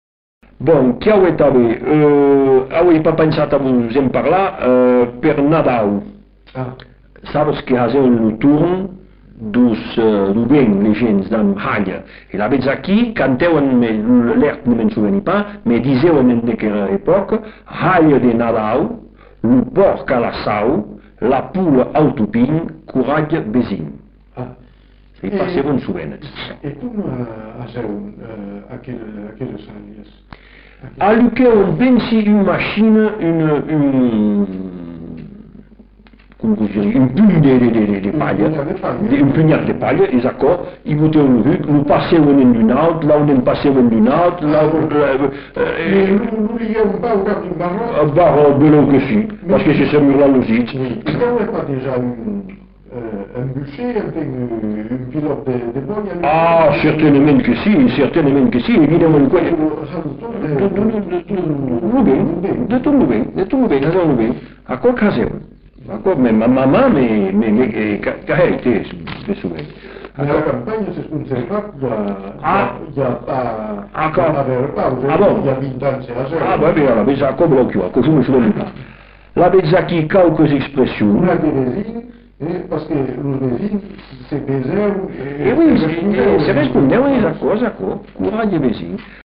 Lieu : Bazas
Genre : forme brève
Type de voix : voix d'homme
Production du son : récité
Classification : formulette